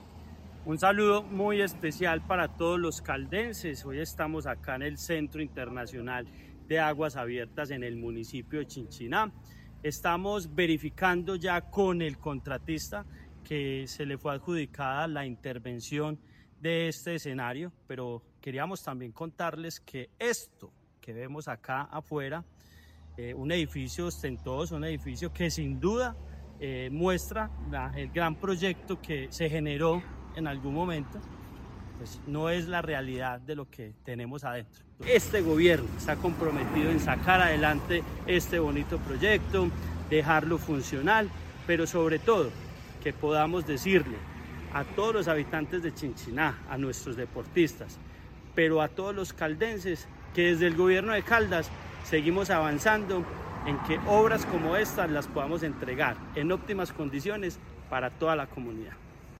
Audio Andrés Duque Osorio, secretario de Deporte, Recreación y Actividad Física de Caldas